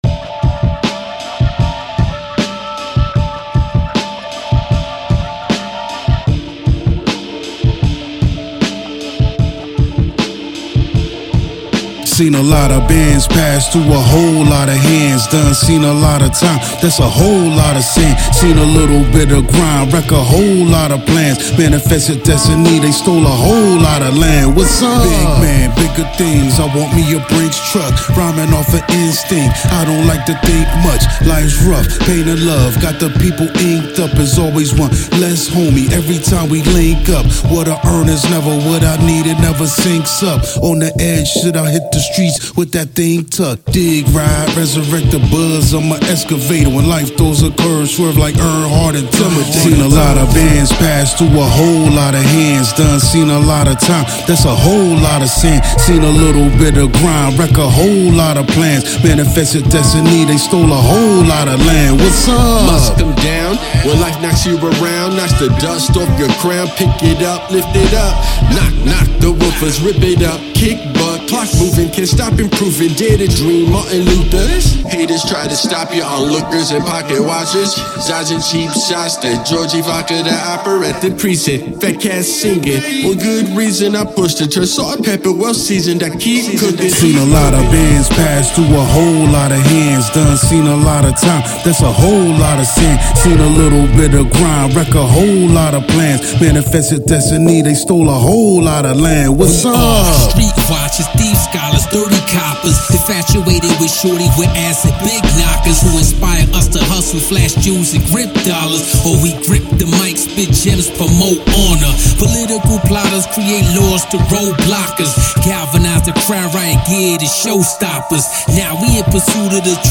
Heavy posse cut